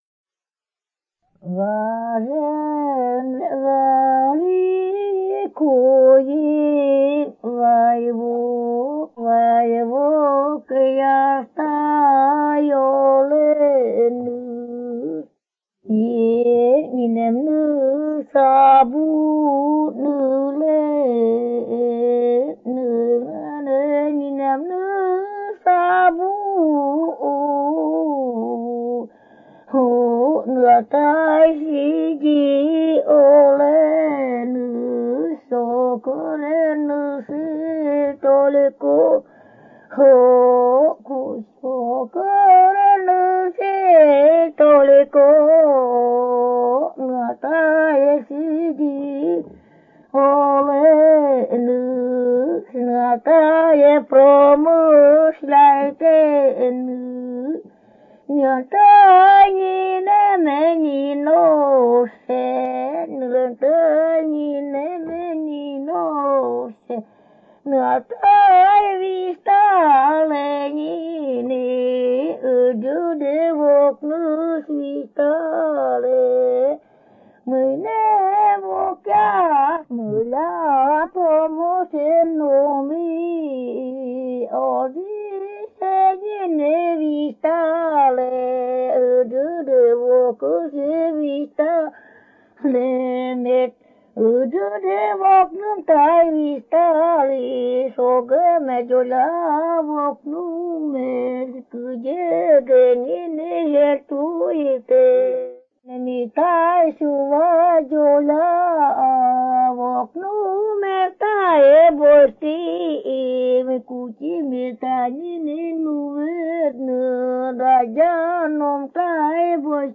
01 Эпическая песня «Куйим Вай вок» (Три брата Вай)